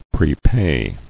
[pre·pay]